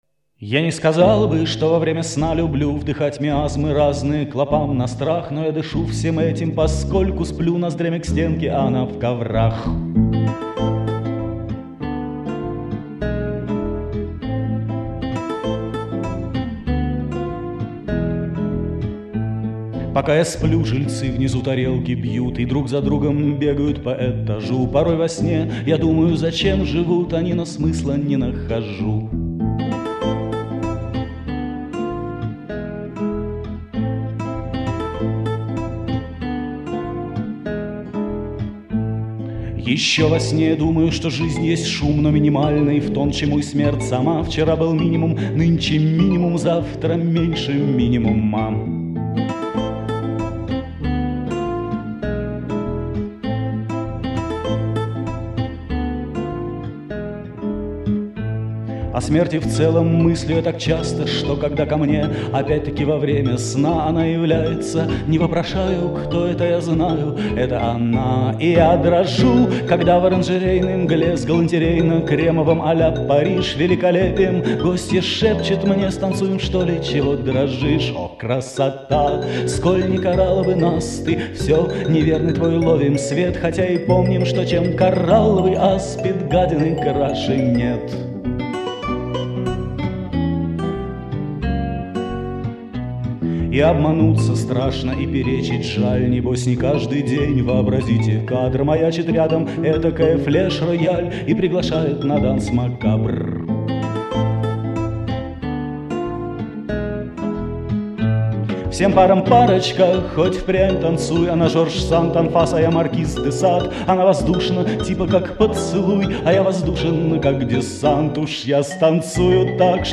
P.S. Вот например, ссылки на песни барда с ведущей аджной: